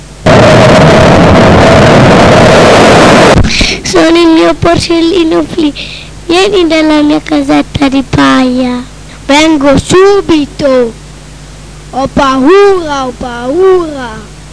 pioggia_2.wav